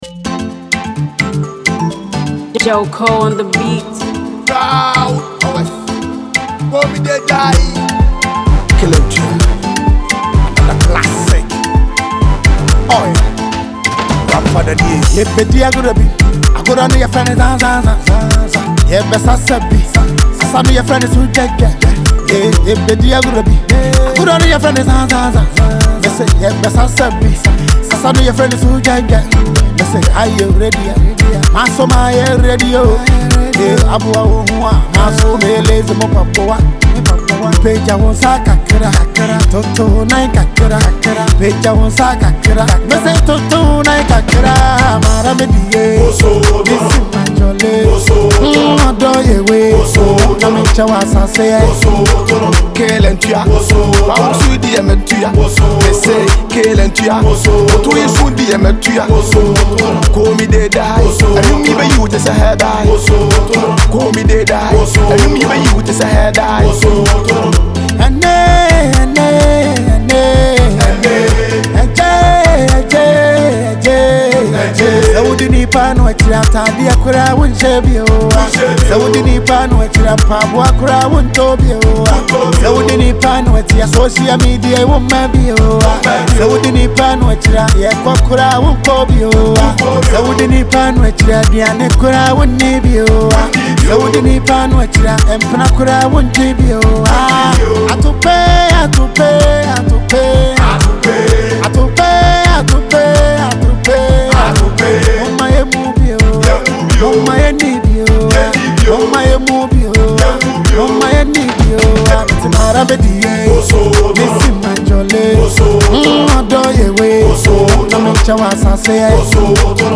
high-energy new single